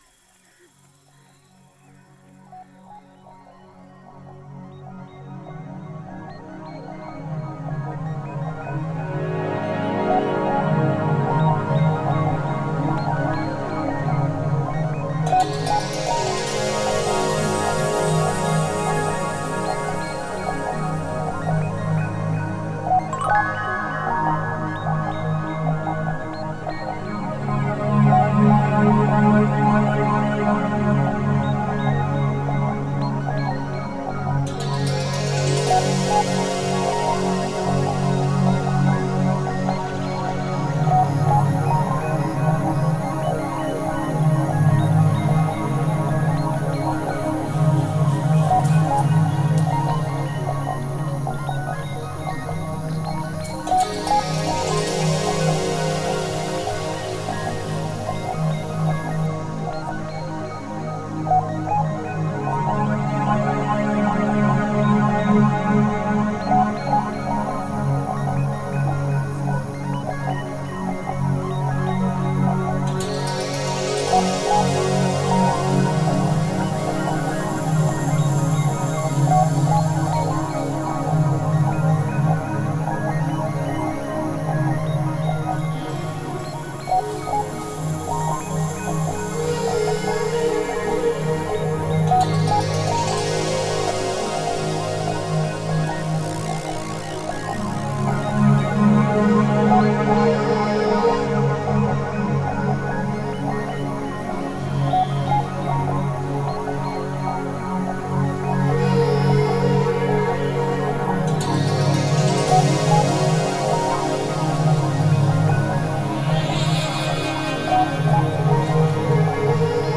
Genre: Deep Ambient.